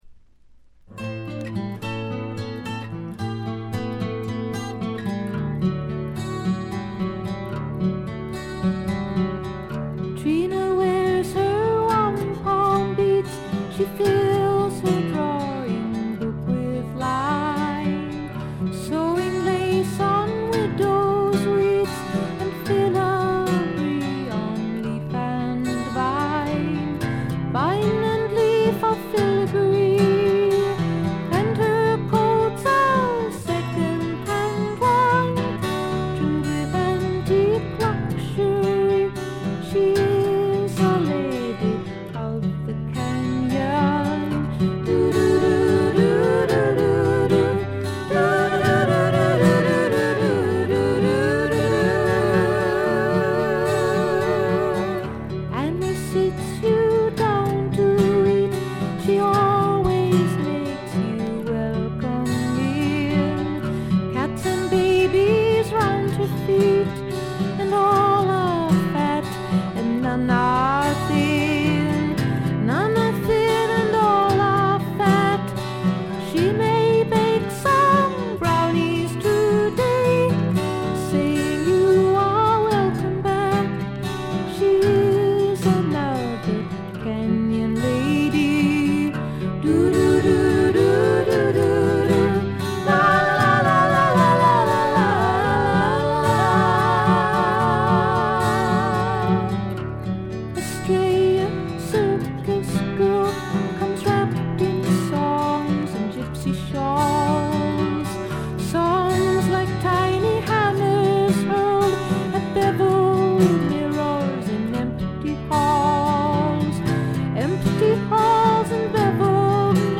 ところどころで軽微なチリプチ。
美しいことこの上ない女性シンガー・ソングライター名作。
試聴曲は現品からの取り込み音源です。